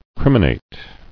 [crim·i·nate]